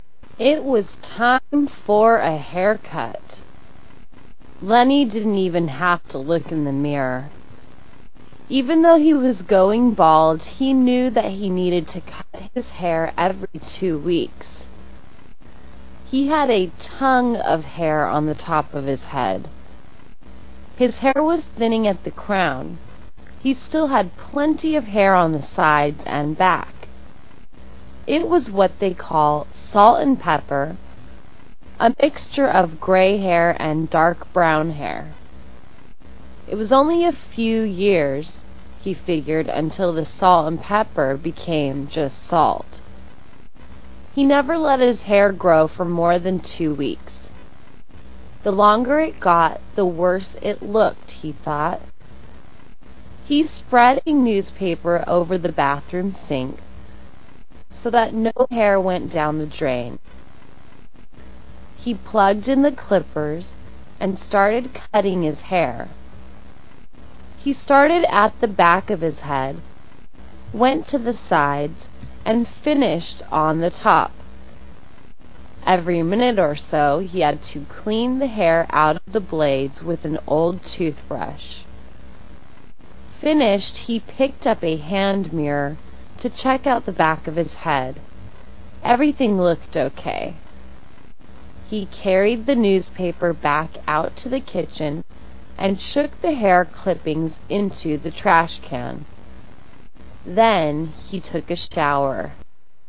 Slow  Stop audio